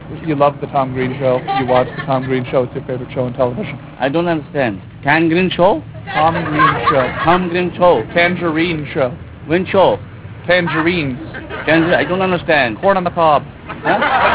Tom is carrying a bright light bulb with him and he is interviewing a man who is having a hard time speaking and understanding english. Tom tells the man that his favorite show is the Tom Green Show.